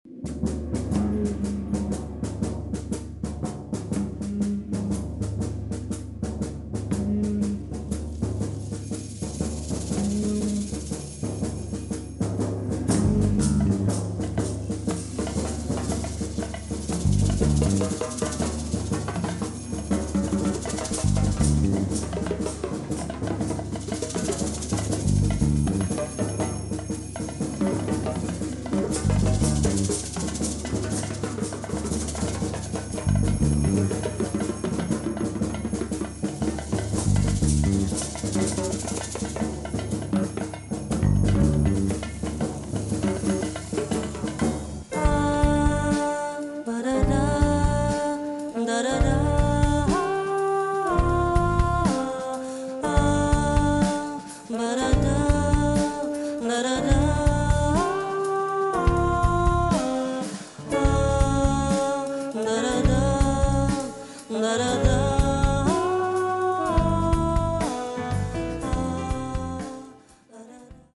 la musica brasiliana di qualità.
Tra brani sognanti